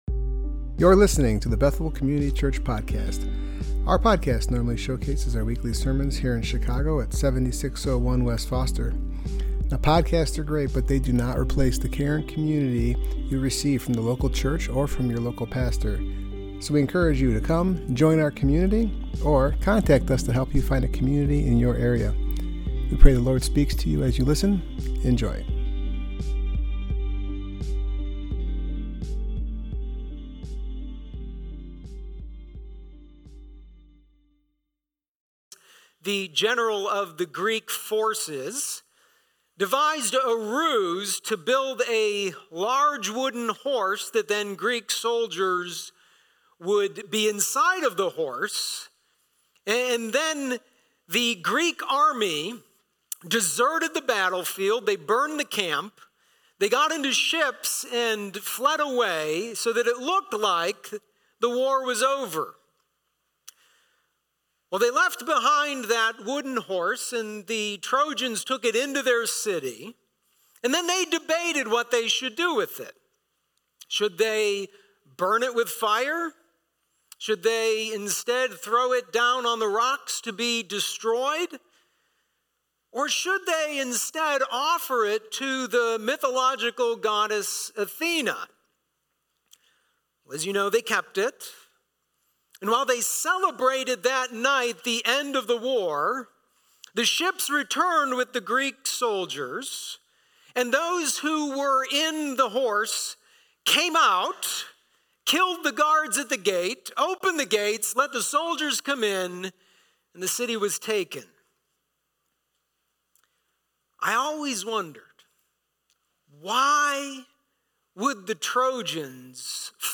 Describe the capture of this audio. Passage: Proverbs 4:1-27 Service Type: Worship Gathering